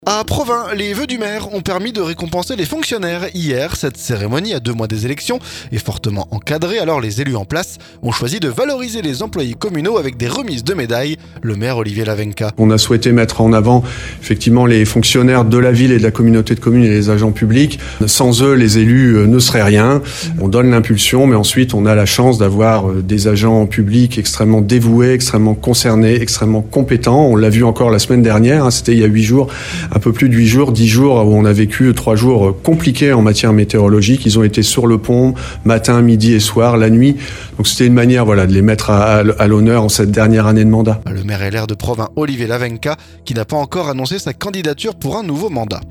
PROVINS - Des fonctionnaires récompensés lors de la cérémonie des vœux